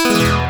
SpeechOff.wav